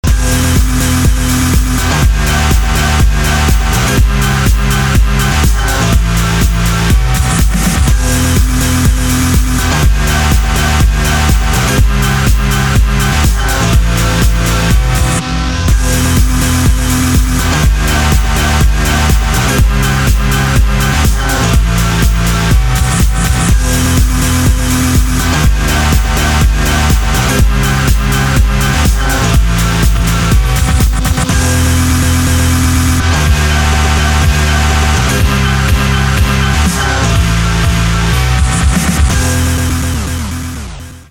• Качество: 224, Stereo
громкие
remix
EDM
без слов
electro house
french house